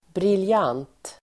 Uttal: [brilj'an:t (el. -'ang:t)]